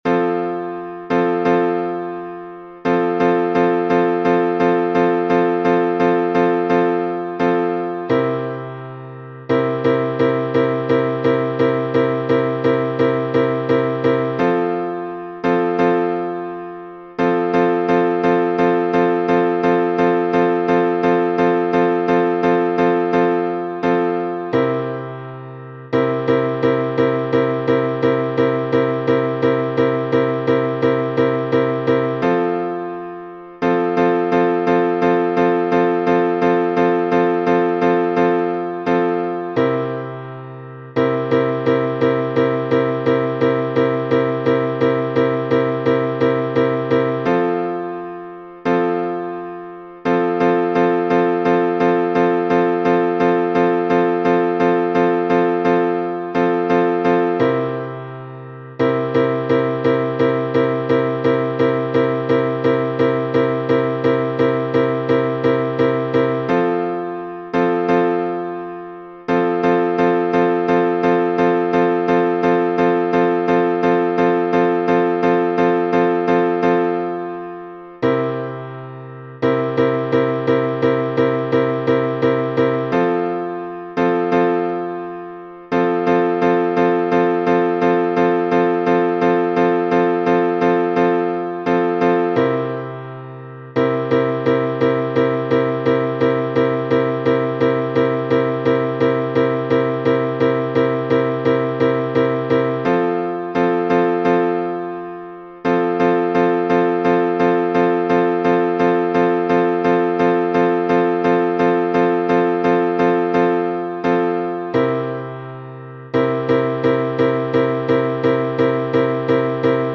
Вернуться в меню · Милость мира Обиходный напев Киевский напев Обиходный напев Текст · PDF · MP3 · Видео · В начало Киевский напев Текст · PDF · MP3 · MSCZ · Видео · В начало Наверх · Вернуться в меню · Милость мира
veruju_obihod.mp3